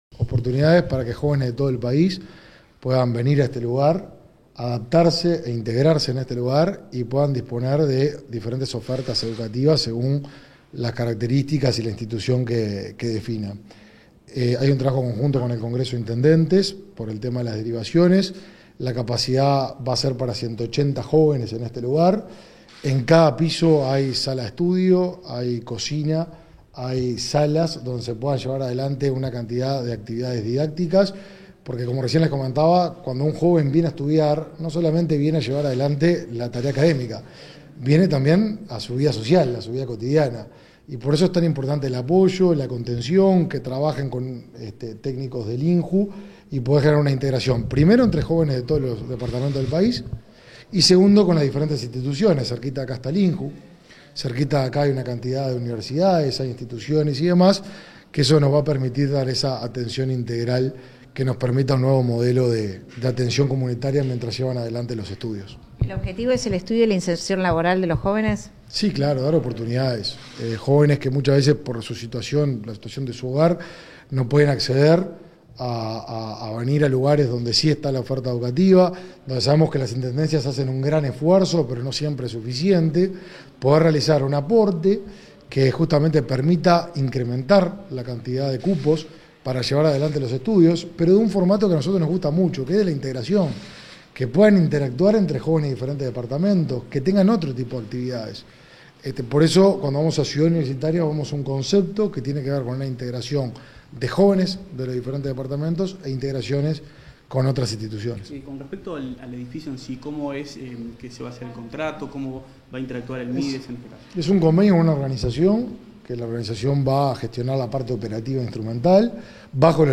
Declaraciones del ministro de Desarrollo Social, Martín Lema
Declaraciones del ministro de Desarrollo Social, Martín Lema 29/08/2022 Compartir Facebook X Copiar enlace WhatsApp LinkedIn Tras la visita a las instalaciones de la Ciudad Universitaria Jorge Larrañaga, el ministro de Desarrollo Social, Martín Lema, realizó declaraciones a la prensa, este 29 de agosto.